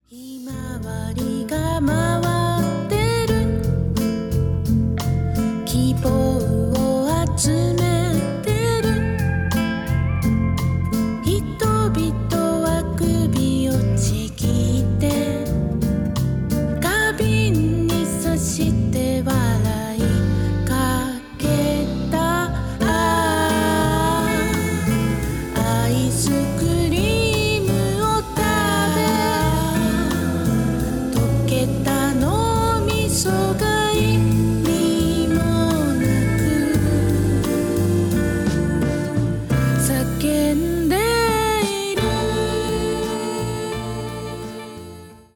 儚くも豊かなメロディーを持つ楽曲
彼らのアングラ的な部分よりも、素朴な中に高い音楽性（と中毒性）が見える、幽玄でメロディアスな好選曲の内容となっています。